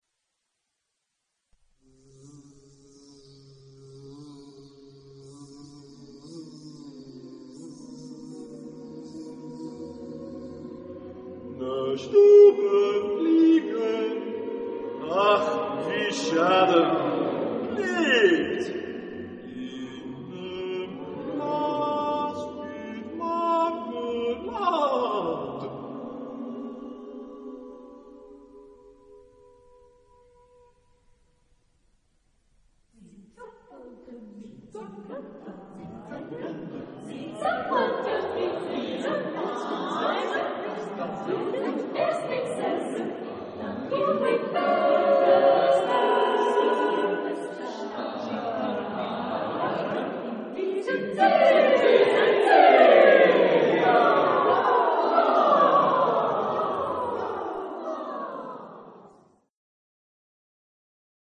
Type de choeur : SATB  (4 voix mixtes )